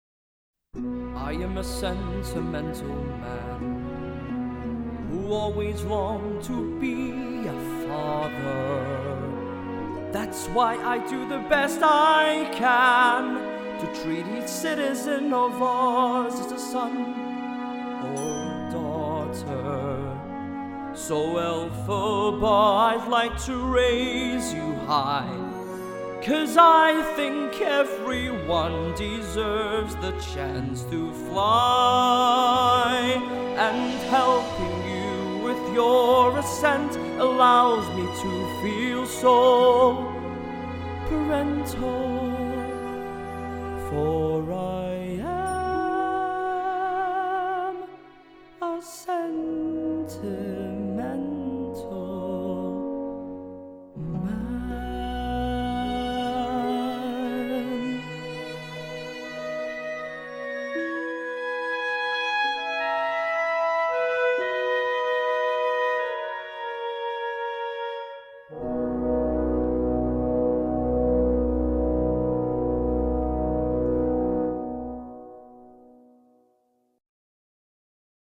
Singing range: Tenor G3-Bb4 (F5 Falsetto)